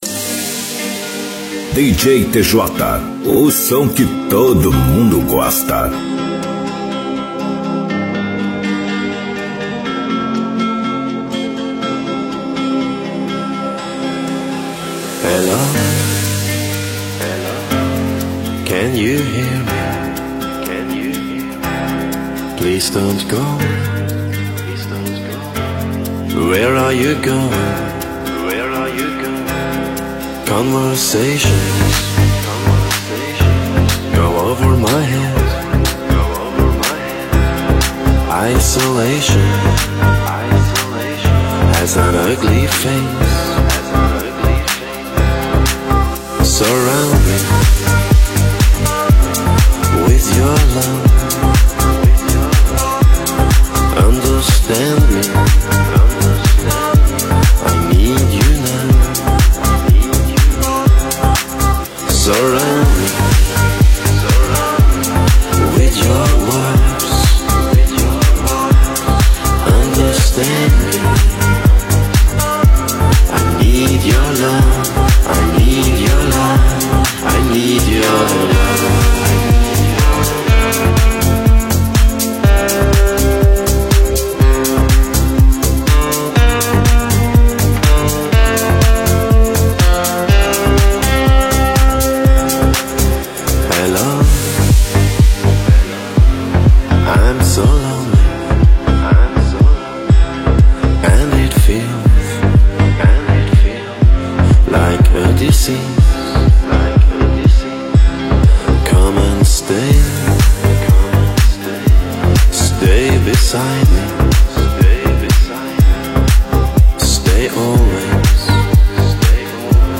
Vem ouvir o puro house 120 Bpm